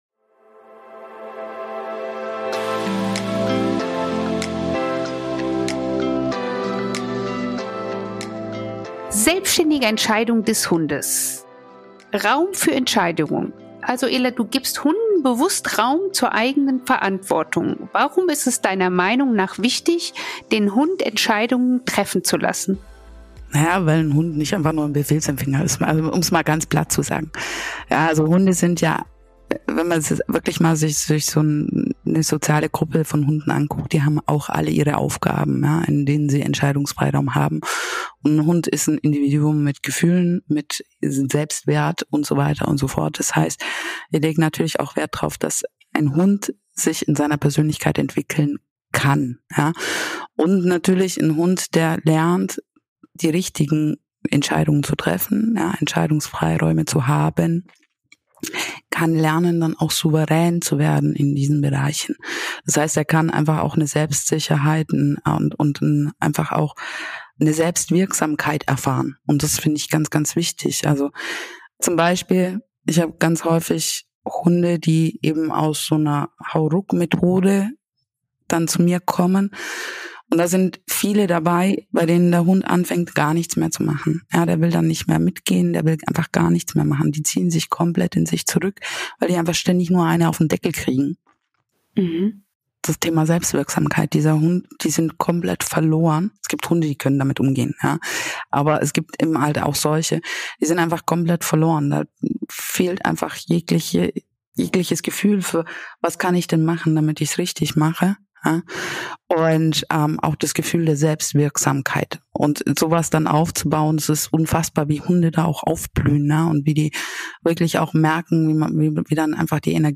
Verbindung von Mensch und Hund (Teil 3) – Interview